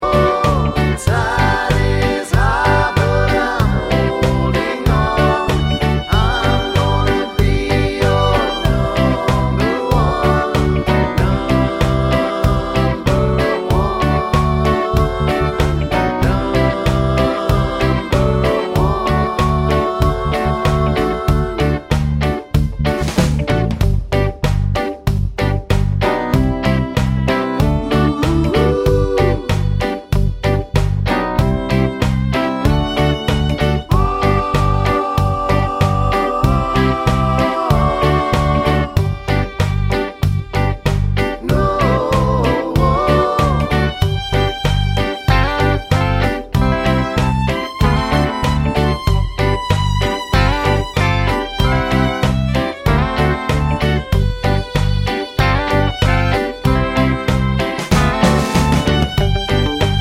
no Backing Vocals Reggae 2:38 Buy £1.50